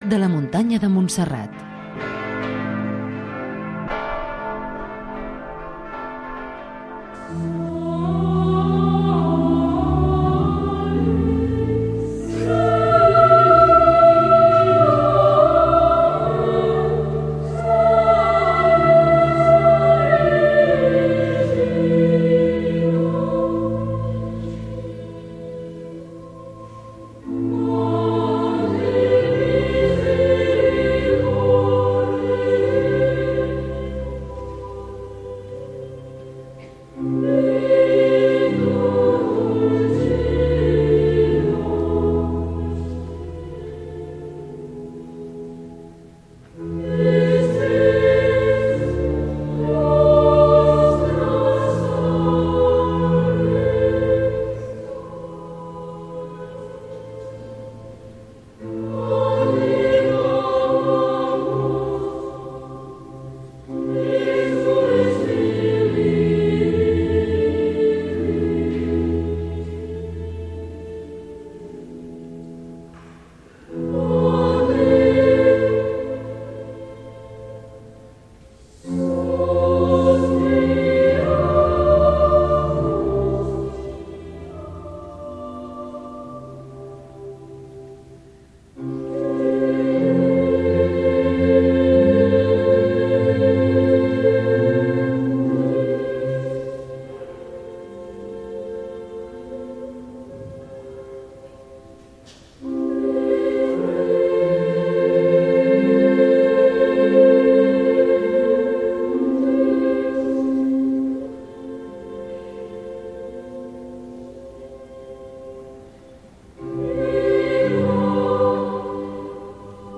amb l'Escolania de Montserrat
L’escolania de Montserrat canta la Salve i el Virolai als peus de la Moreneta, com un moment de pregària al migdia, en el qual hi participen molts fidels, pelegrins i turistes que poden arribar omplir a vessar la basílica.